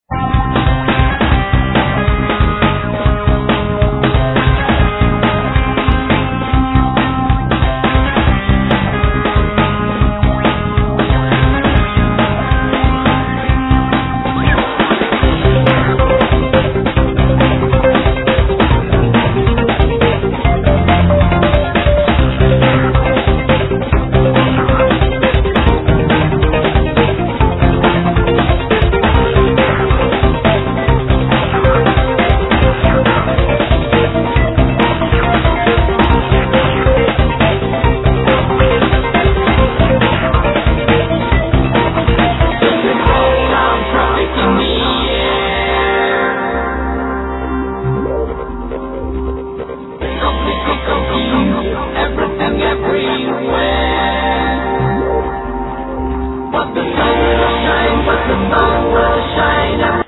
Vocals, Guitar
Bass
Guitar, Guitar synth
Keyboards
Violin
Percussions